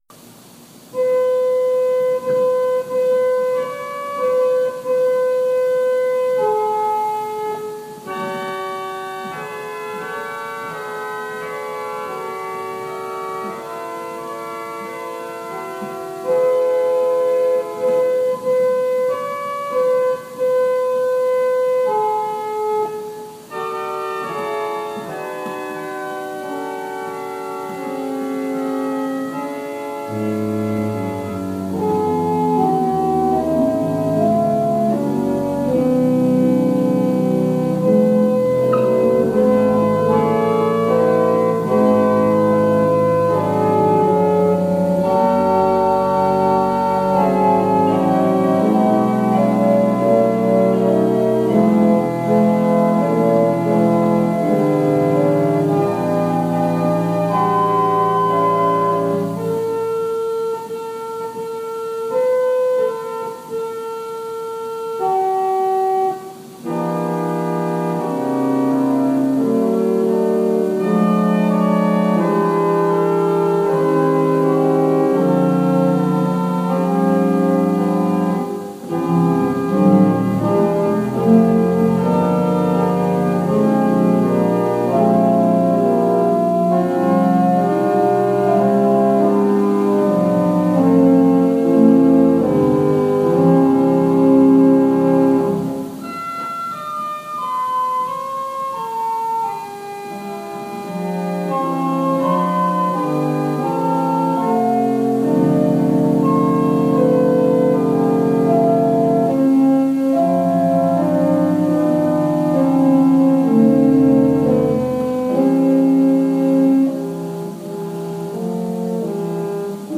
Worship Service December 6, 2020 | First Baptist Church, Malden, Massachusetts
Call to Worship / Invocation / Lord’s Prayer